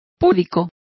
Complete with pronunciation of the translation of chaste.